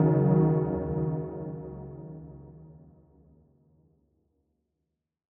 Index of /DESN275/loops/Loop Set - Spring - New Age Ambient Loops/Tail Samples
MindMap_90_B_PadTail.wav